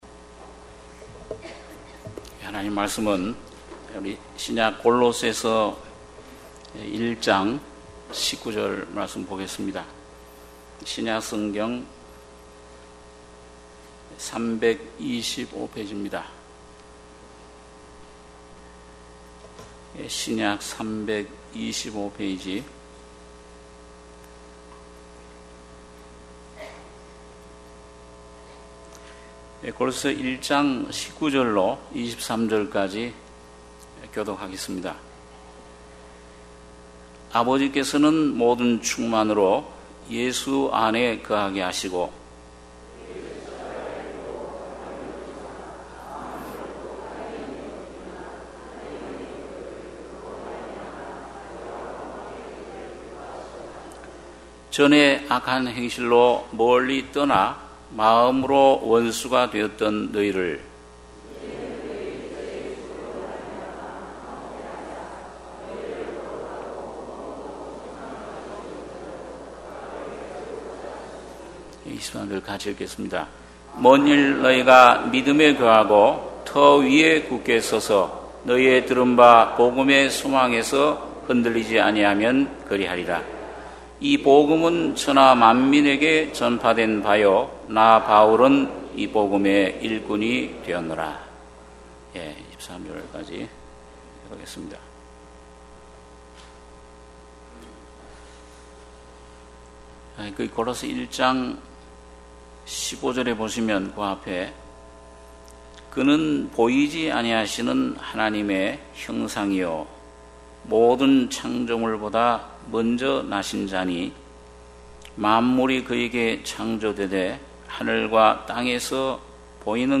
주일예배 - 골로새서 1장 19-23절